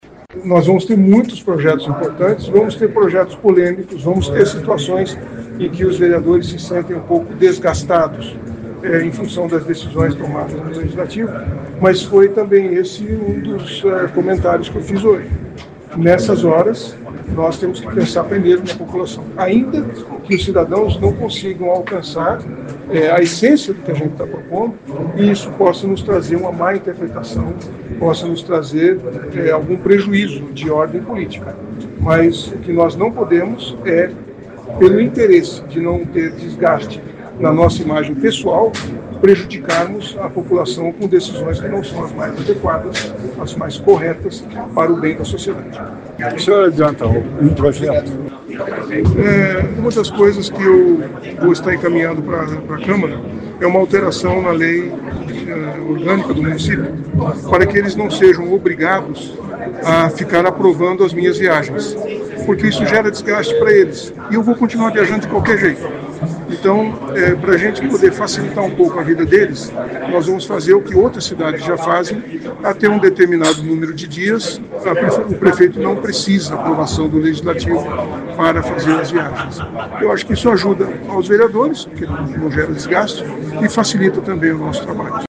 O prefeito Sílvio Barros abriu os trabalhos do Legislativo Municipal nesta terça-feira (3), primeira sessão ordinária do ano legislativo.